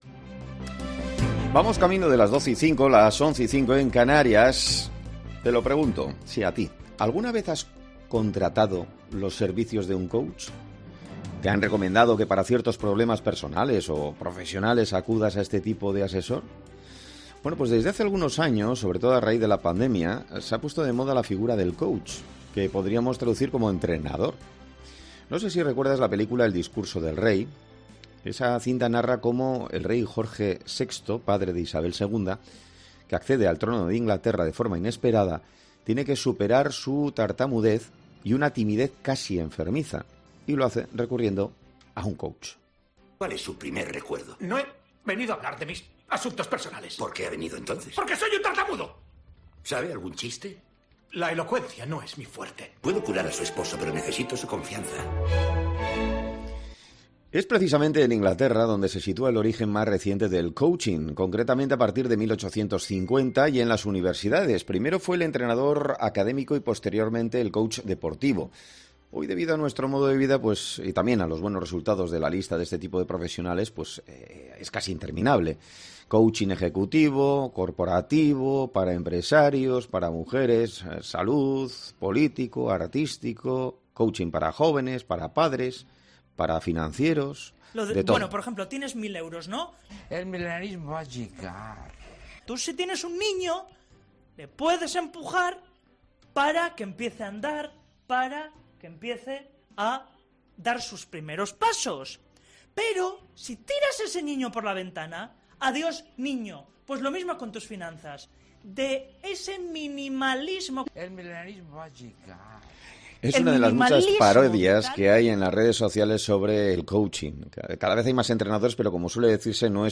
'Herrera en COPE' entrevista a un experto en el mundo de coaching que explica la razón de la proliferación en masa de coaches en estos últimos años